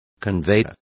Complete with pronunciation of the translation of conveyors.